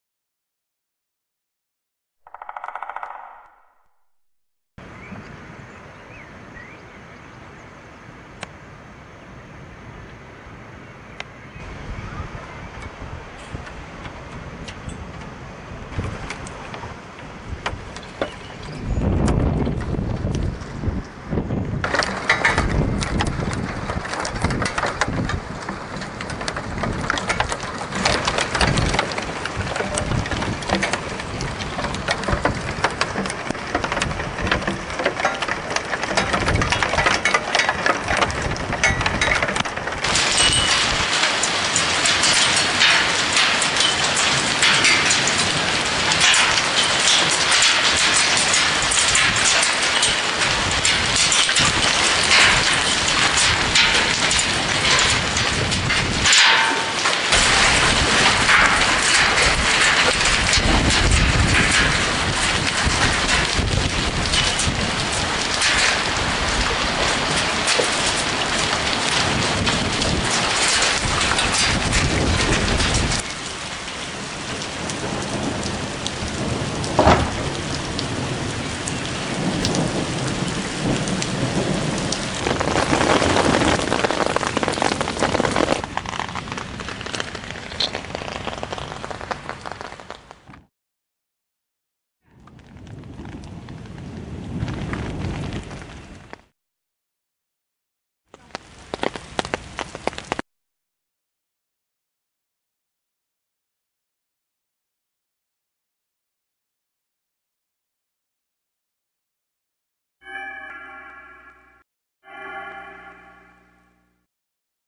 Hagelgewitter 08.05.2003